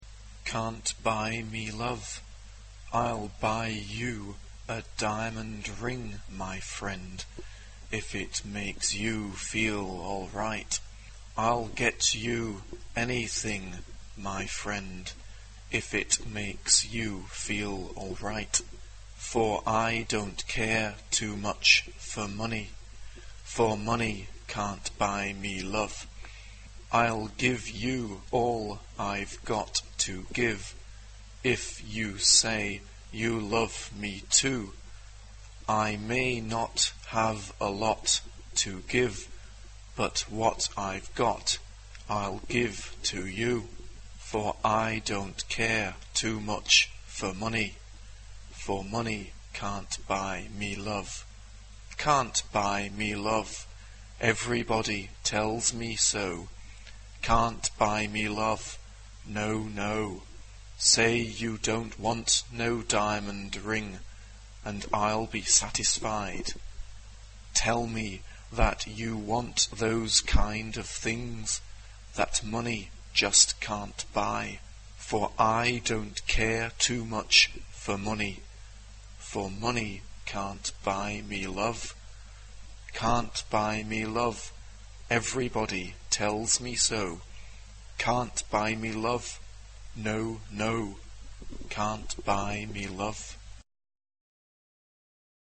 ... transcription pour orgue seul ...
Profane ; Pop ; Chanson ; Rock
interrogatif ; chaleureux
Orgue
Tonalité : ré majeur